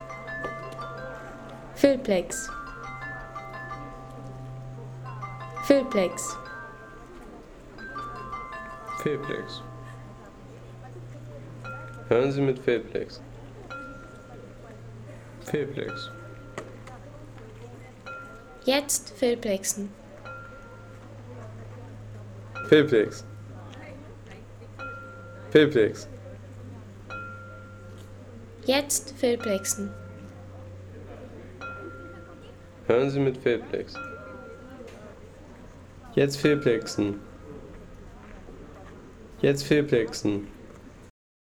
Meißner Porzellanglocken
Meißner Porzellanglocken – Das Glockenspiel im Zwingerhof.